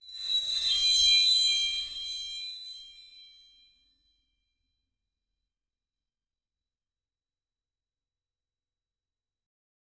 soft-welcome-chime-with-s-3y35m4z5.wav